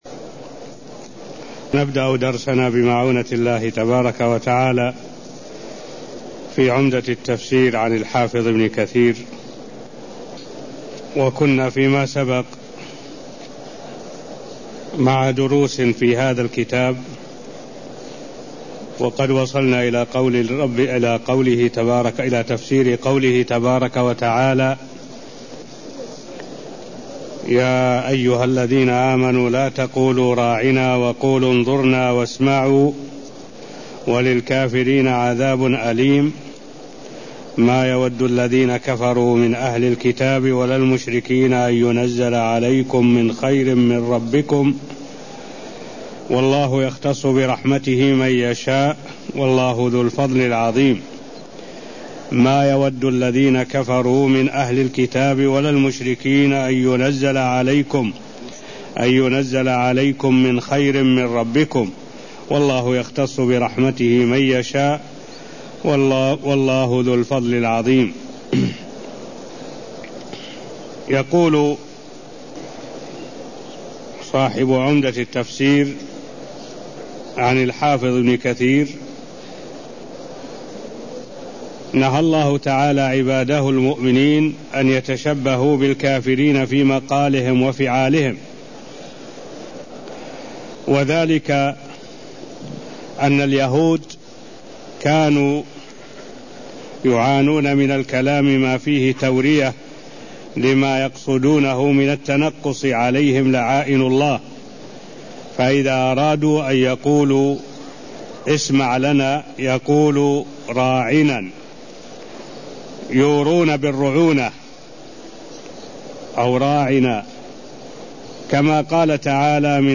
المكان: المسجد النبوي الشيخ: معالي الشيخ الدكتور صالح بن عبد الله العبود معالي الشيخ الدكتور صالح بن عبد الله العبود تفسير آية104ـ105 من سورة البقرة (0058)